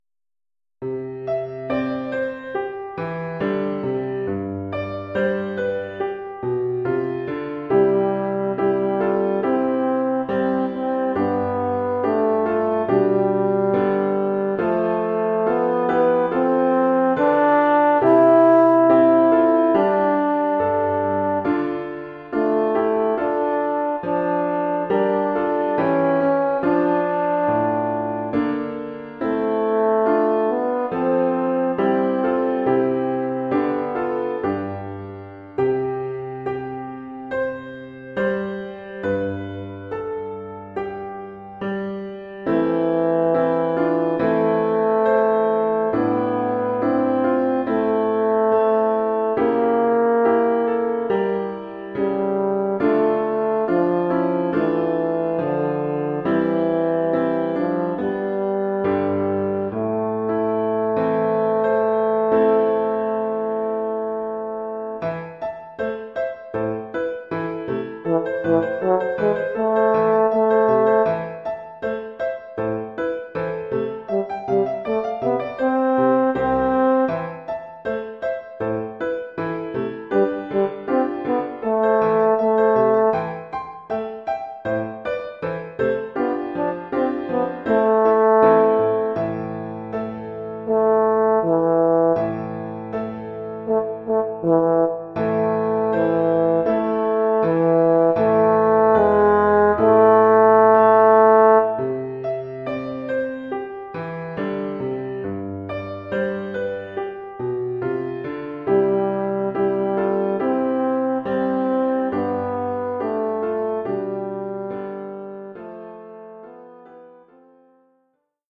Formule instrumentale : Cor et piano
Oeuvre pour cor d’harmonie et piano.